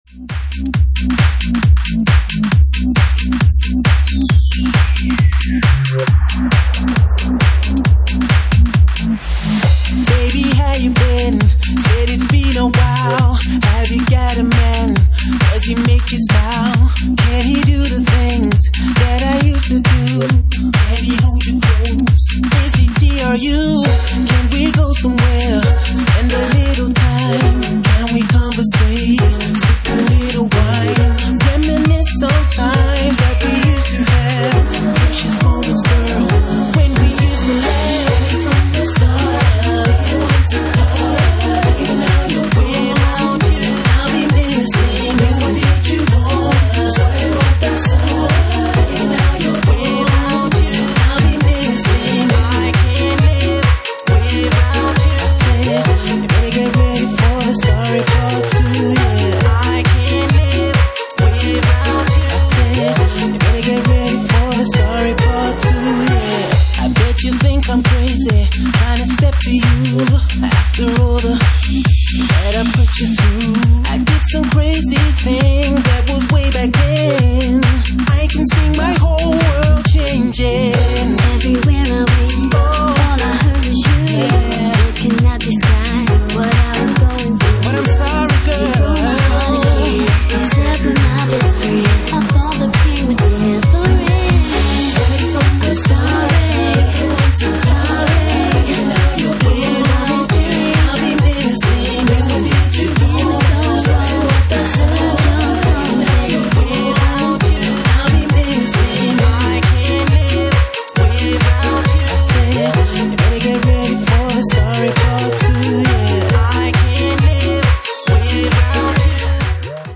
Genre:Bassline House
Bassline House at 135 bpm
Original Mix